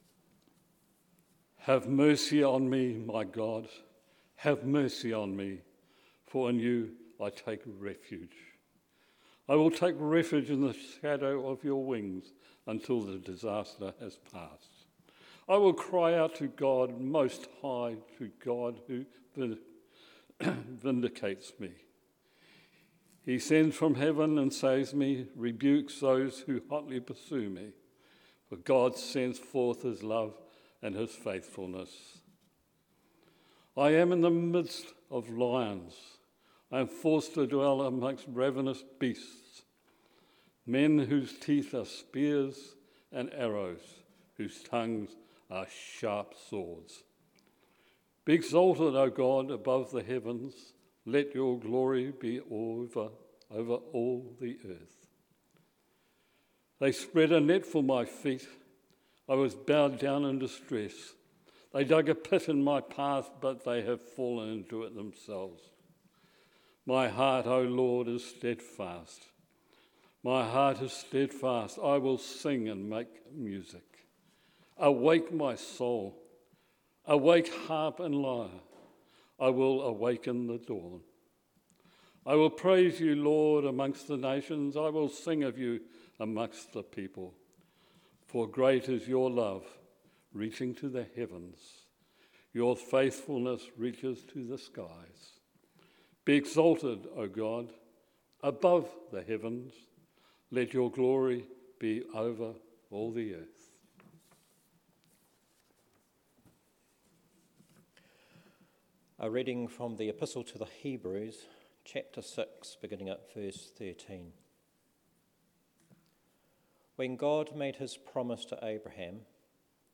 A homily for Evensong